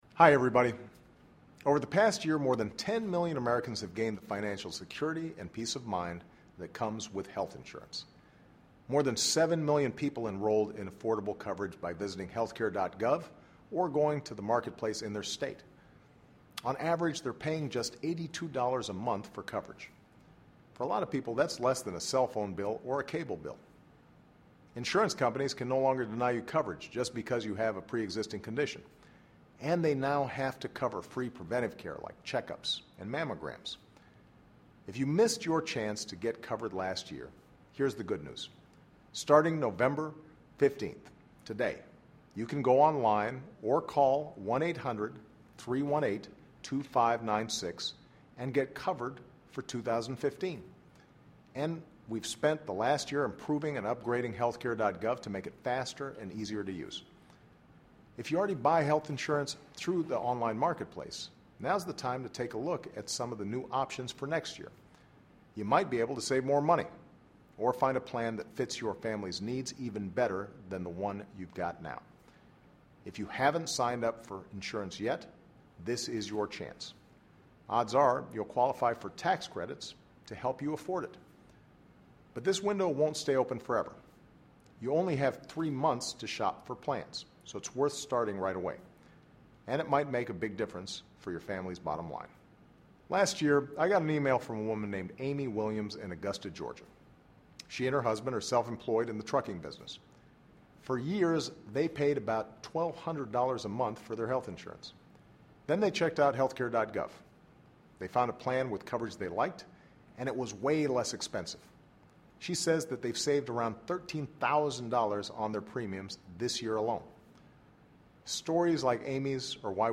President Barack Obama tapes the Weekly Address in the Roosevelt Room of the White House, Nov. 6, 2014